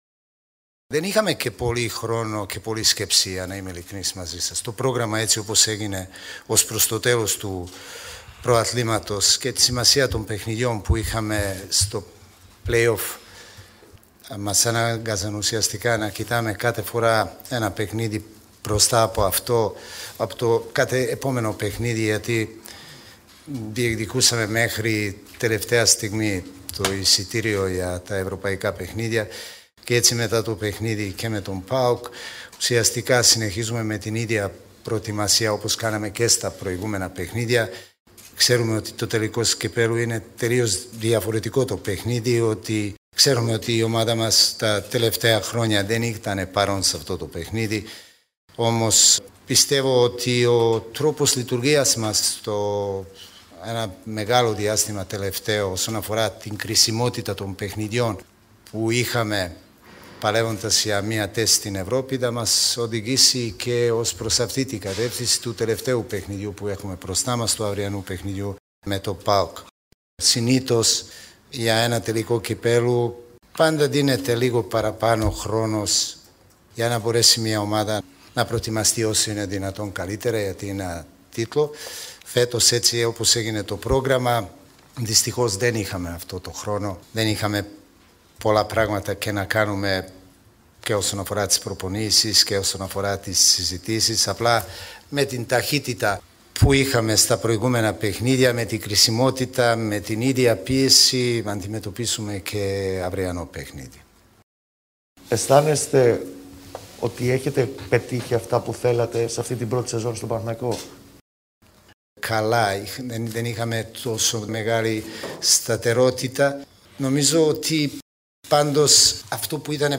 Για την προετοιμασία της ομάδας ενόψει του τελικού του Κυπέλλου Ελλάδας μίλησε ο προπονητής του «τριφυλλιού» στη συνέντευξη Τύπου του αγώνα.
Ακούστε τις δηλώσεις του Ιβάν Γιοβάνοβιτς: